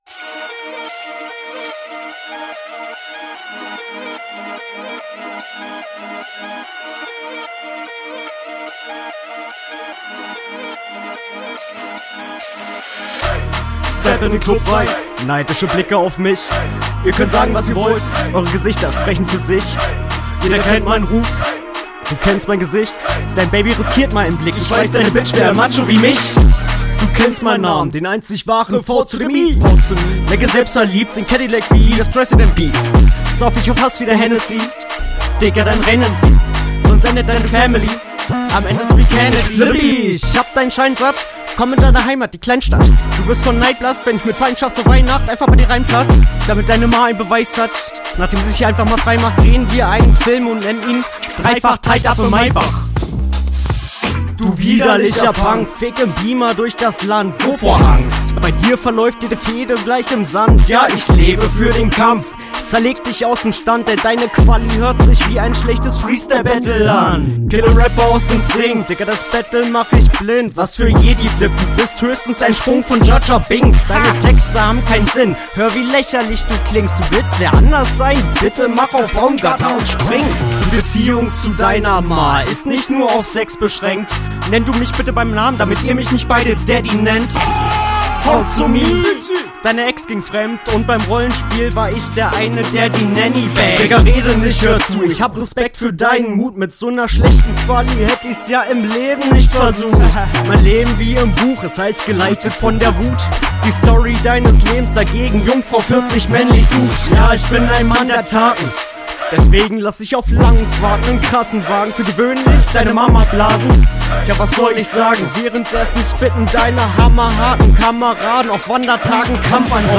Soundquality das selbe wie letzte Runde.